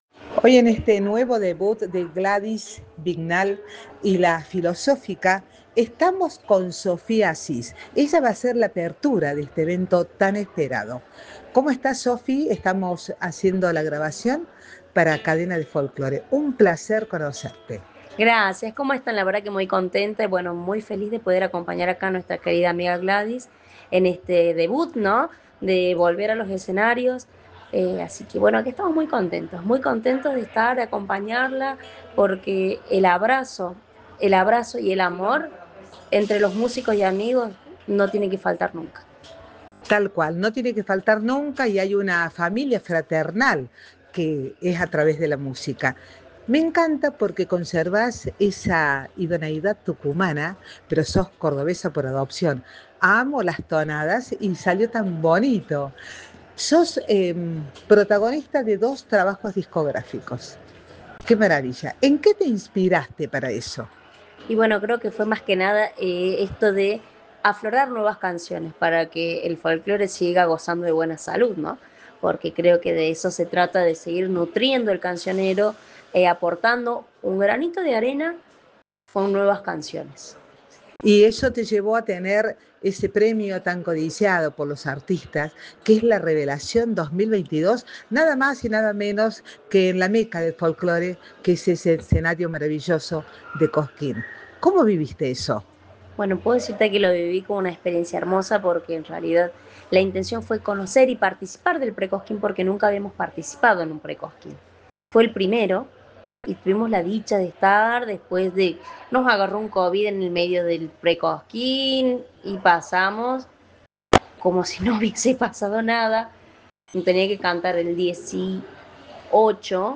se presento este sábado en una jornada musical
como la magnifica cantautora Argentina y Tucumana